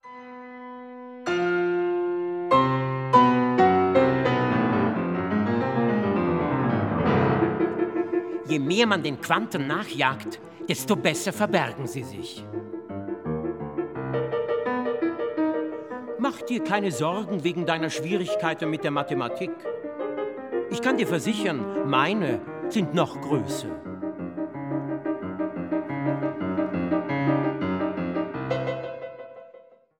Klavier
Werke für Sprecher und Klavier.